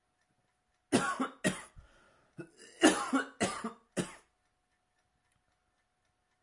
声乐 " 男子咳嗽 1
描述：一个人反复咳嗽。
标签： 扼流圈 咳嗽 咳嗽
声道立体声